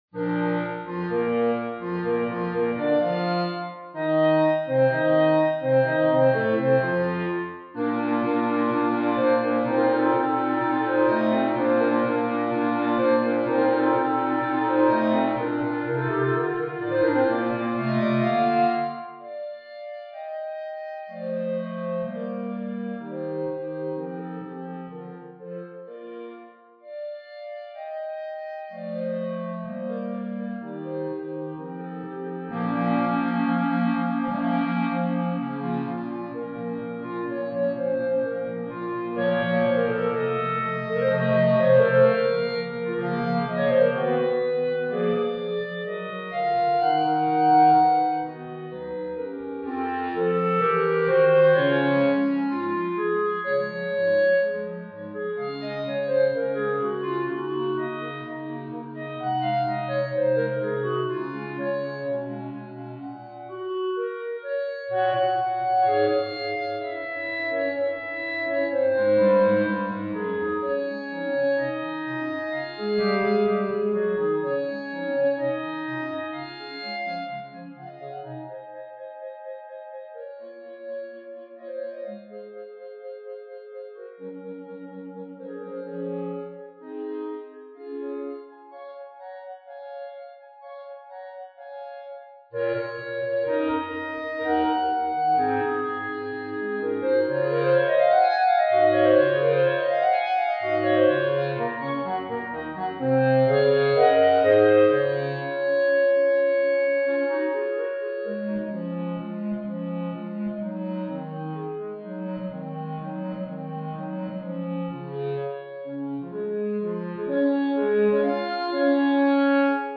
B♭ Clarinet 1 B♭ Clarinet 2 B♭ Clarinet 3 Bass Clarinet
单簧管四重奏
风格： 古典